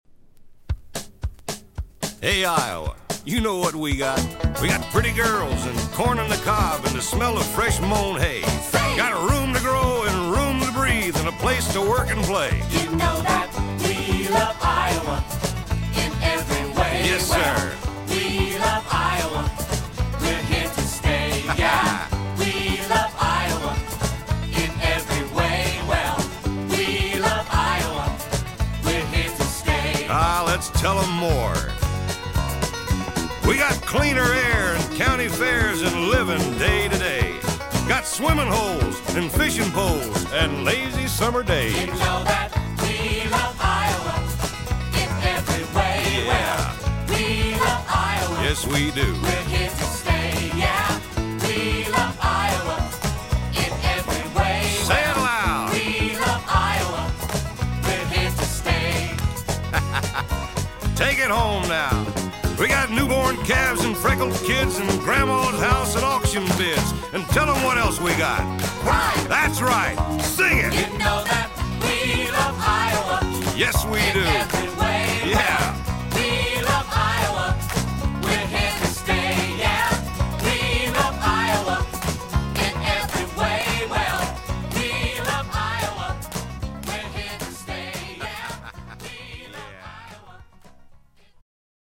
This folk music
drums
piano
bass guitar
harmonica
banjo
strings
vocals
Folk music--Iowa